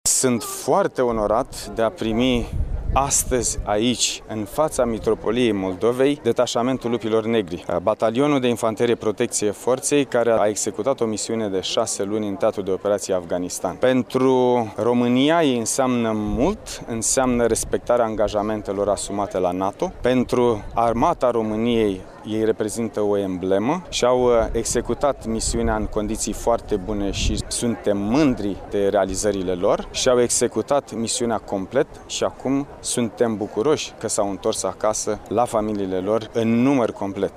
Ceremonia de întoarcere din teatrele de operaţiuni din Afganistan a soldaţilor din Batalionul 151 Infanterie a avut loc, astăzi, pe pietonalul Ştefan cel Mare din Iaşi, în prezenţa oficialităţilor locale, judeţene şi naţionale.